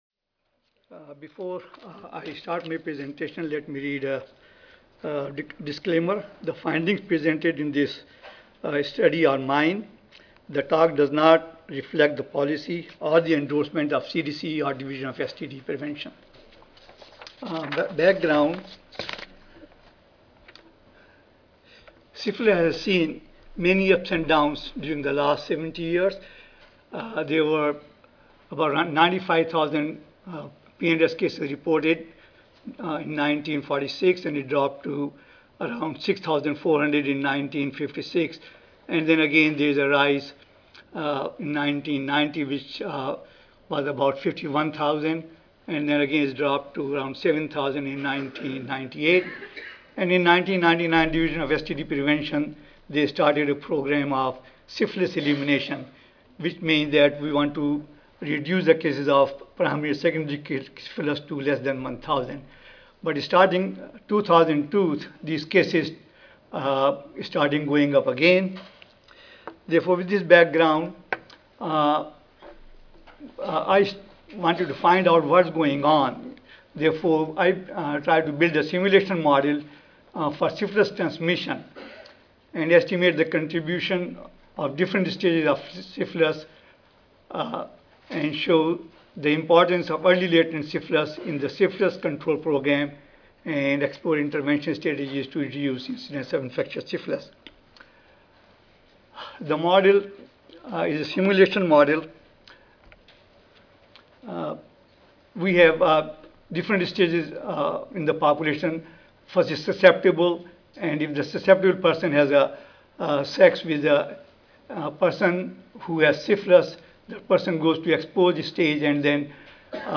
2010 National STD Prevention Conference: Current Issues in Syphilis Prevention and Control
GA Audio File Recorded presentation